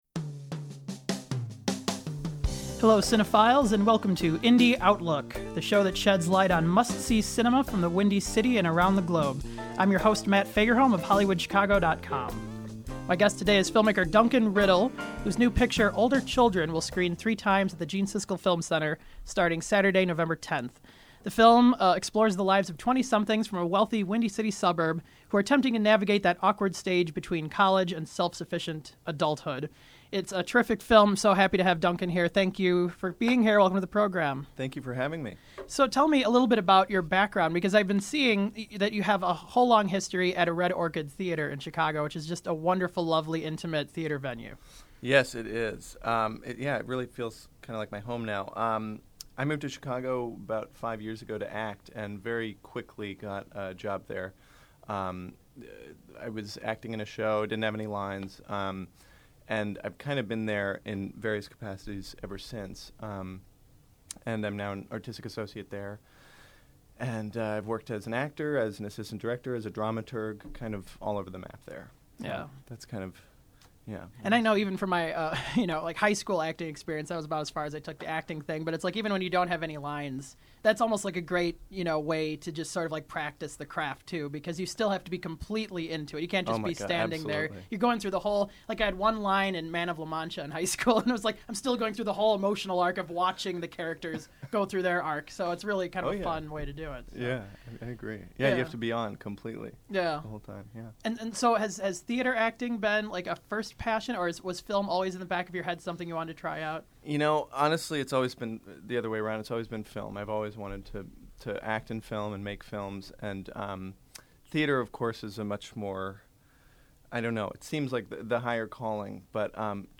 The program was produced at Columbia College Chicago.